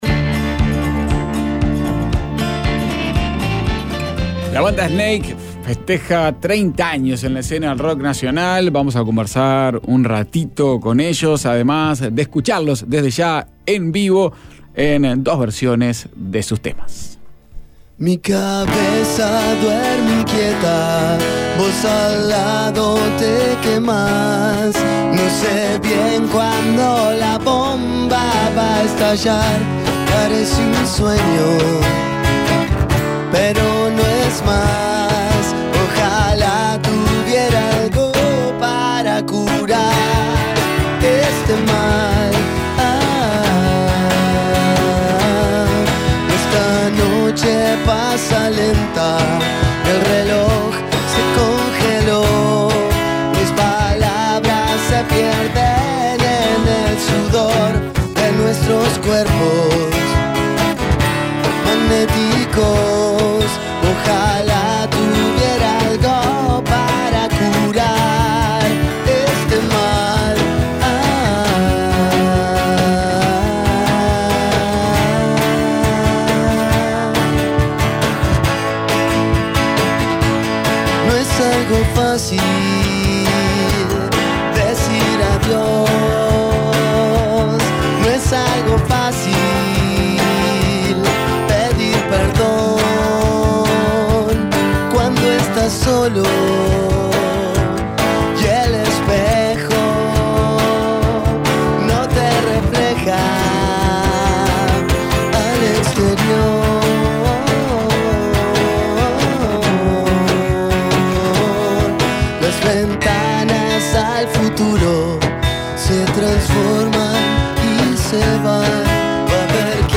Además, cantaron en vivo El gran escape y Fácil.